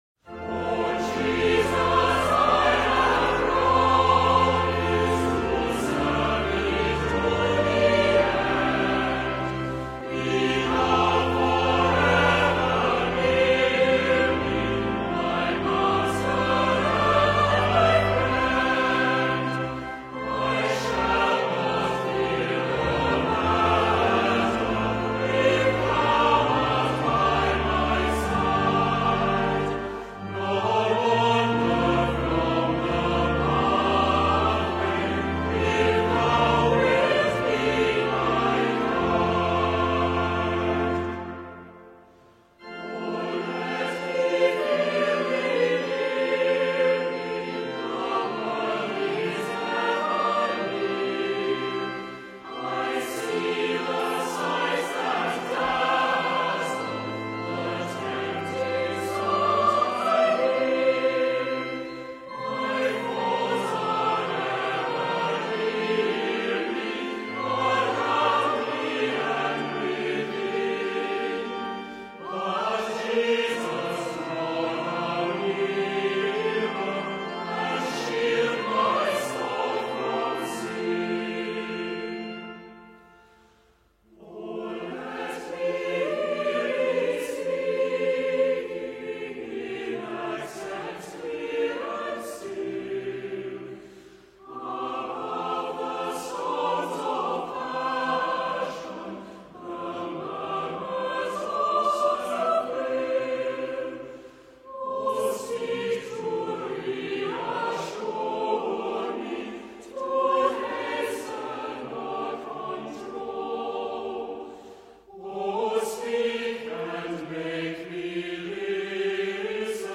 CLOSING HYMN  O Jesus, I Have Promised       Music: James William Elliott (1874)
Scottish Festival Singers
O-Jesus-I-Have-Promised-Scottish-Festival-Singers.mp3